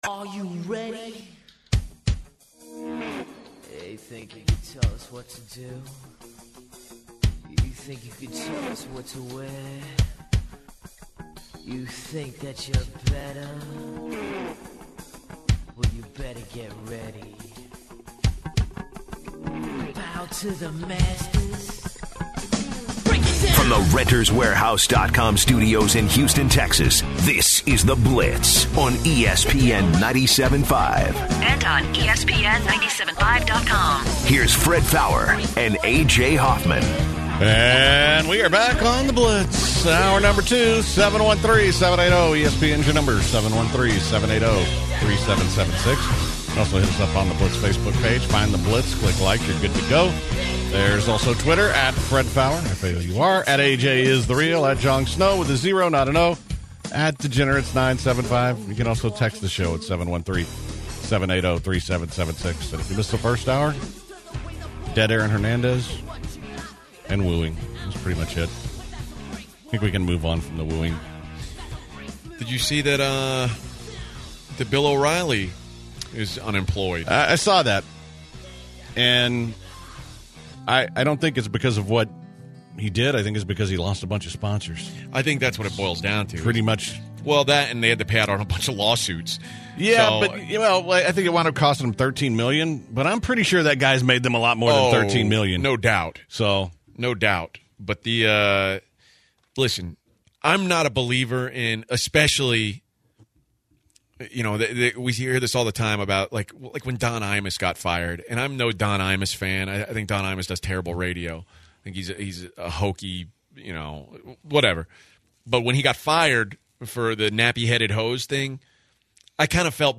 Plenty of calls in hour #2.